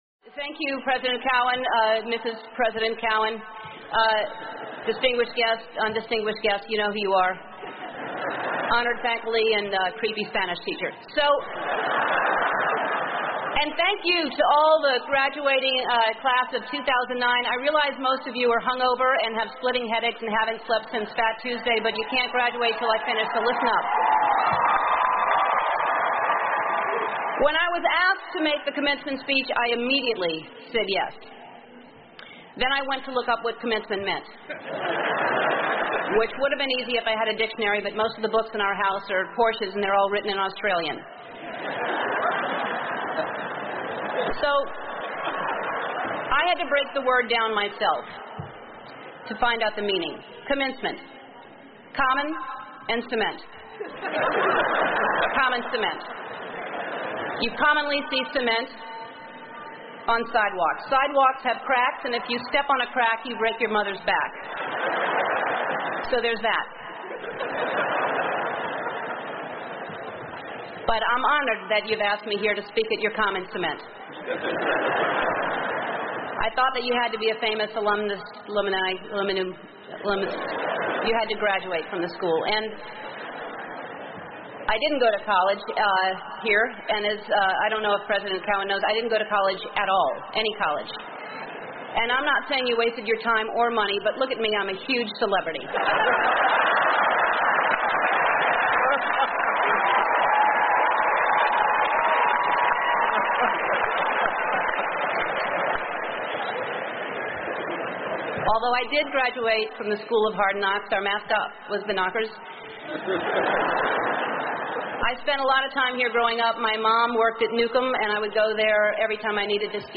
欧美人文风情第42篇:脱口秀女王Ellen的幽默毕业演说 听力文件下载—在线英语听力室